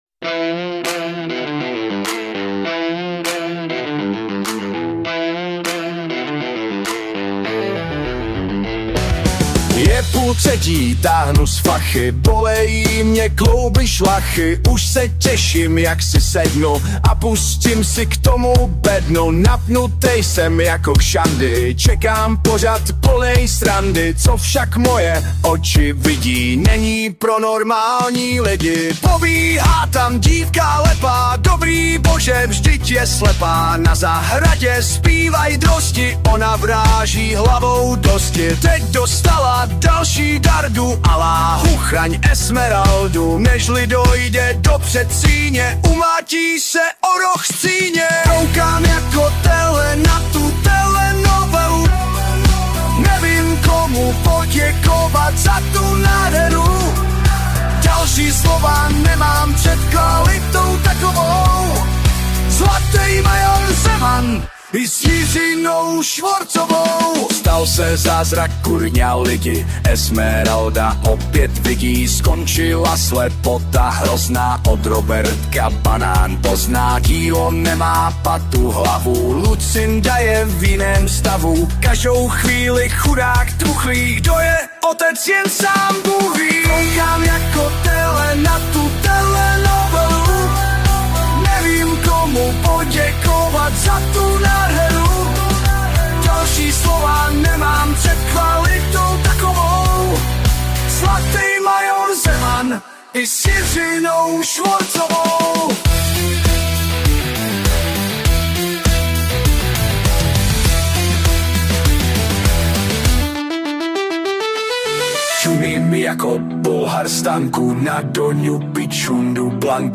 Humor
zpěv a hudba: AI